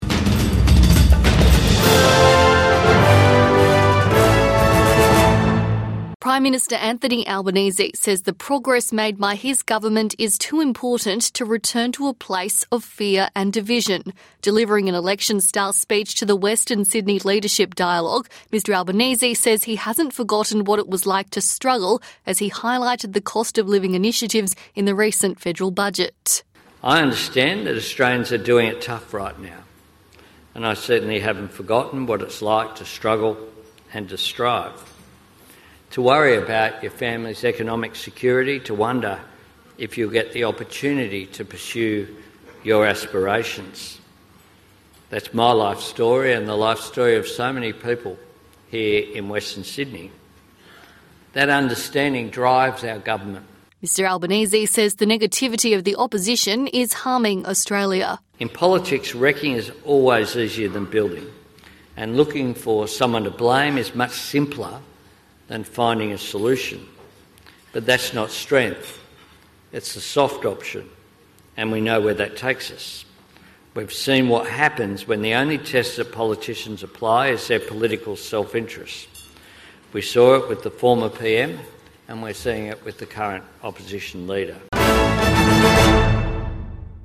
Anthony Albanese addresses cost-of-living pressures in Western Sydney speech